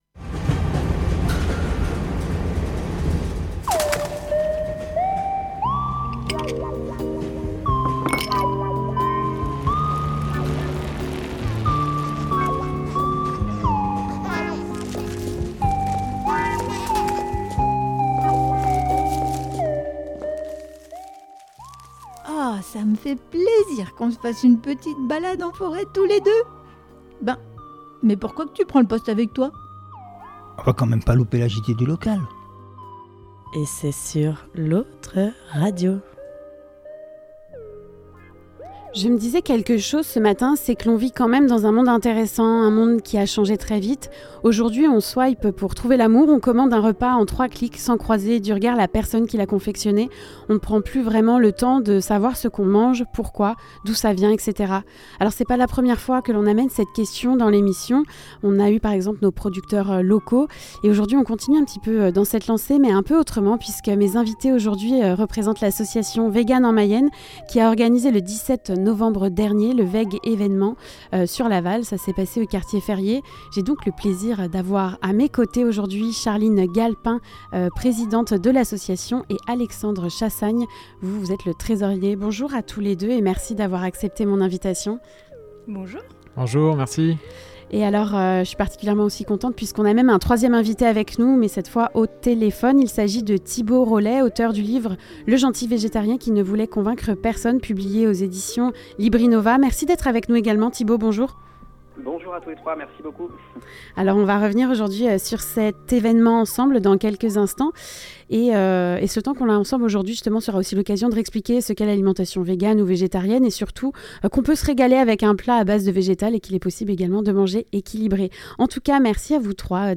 A l'occasion des actions menées autour de la journée internationale de lutte contre les violences faites aux femmes, sur Laval Agglo. 11 témoignages écrits de femmes ont été recueillis par L'Autre Radio et ont ensuite été lu par les membres de l'équipe Sorcières.